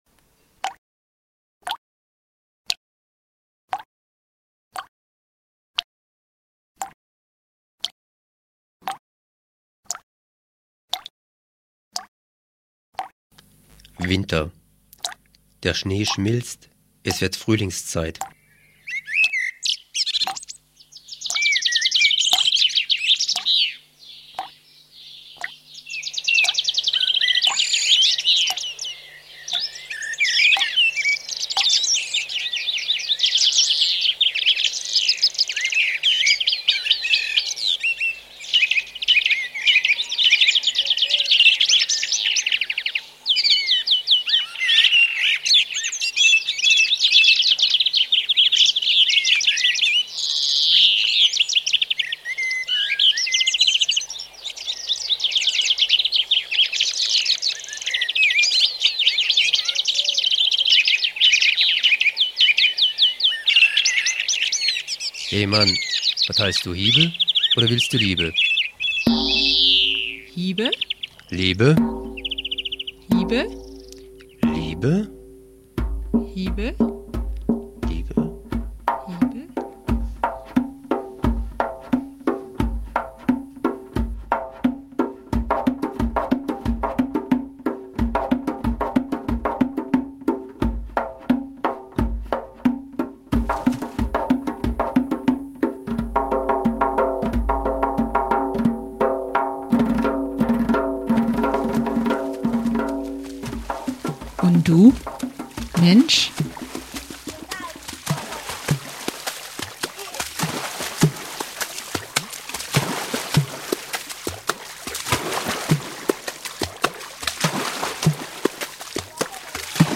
Da werden Lieder gesungen, gezwitschert, geträllert; Lieder von Vögeln in Liebe und Krieg.